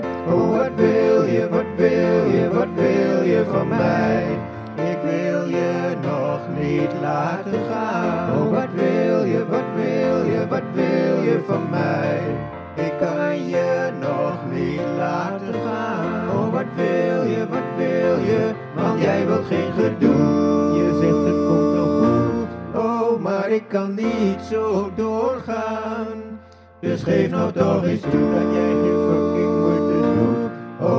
SSATB-arrangement
SSATB arrangement